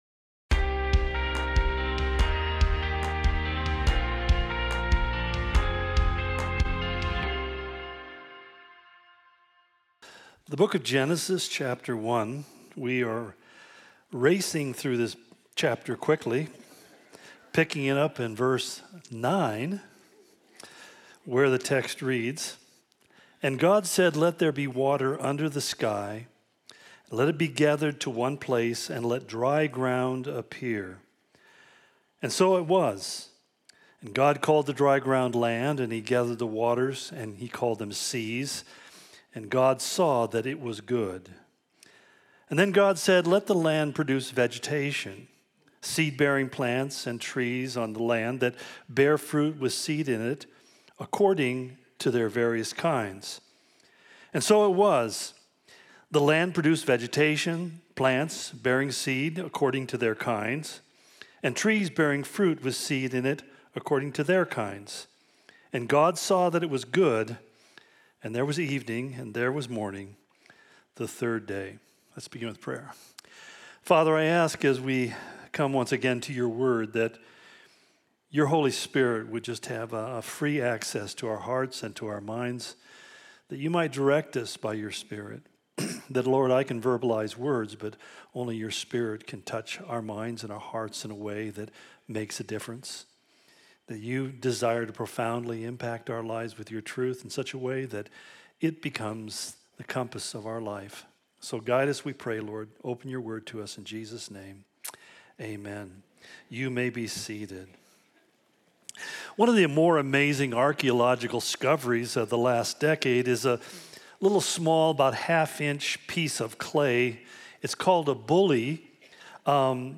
The Origin Of Everything - Part 4 The 'Twice Good Day' Calvary Spokane Sermon Of The Week podcast